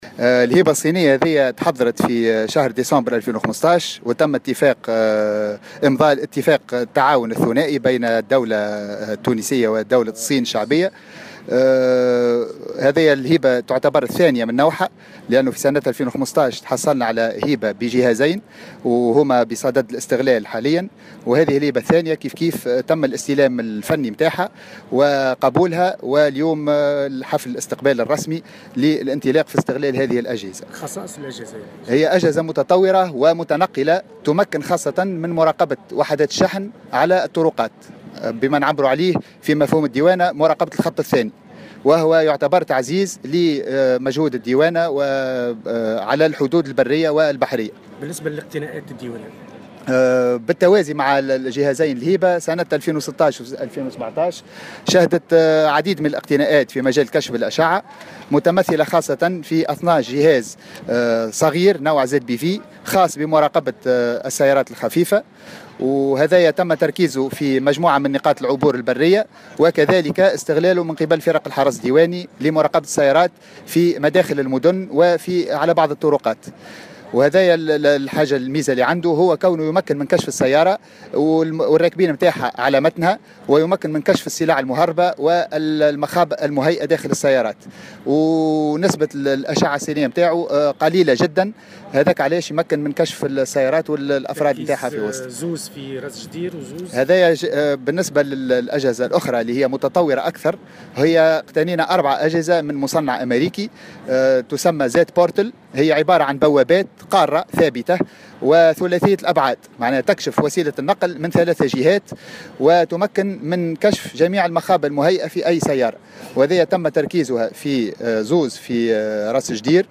في تصريح لمراسل الجوهرة أف أم